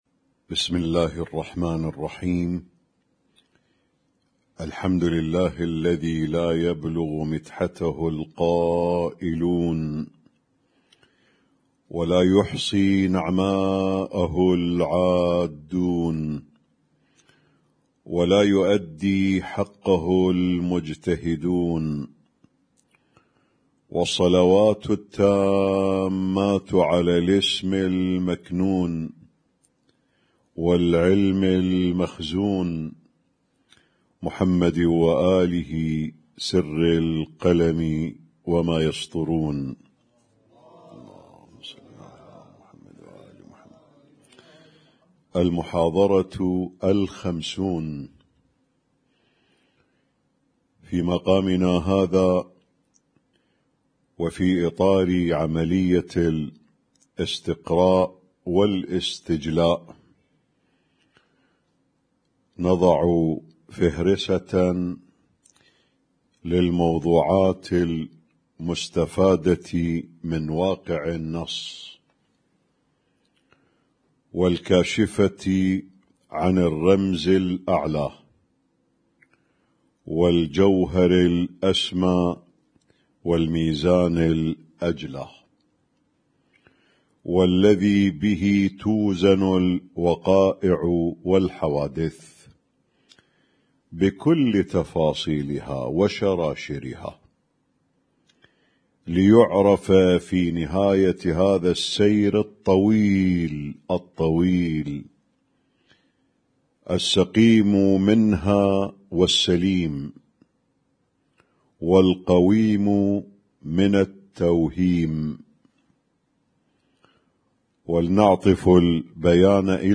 الدرس الخمسون
اسم التصنيف: المـكتبة الصــوتيه >> الدروس الصوتية >> الرؤية المعرفية الهادفة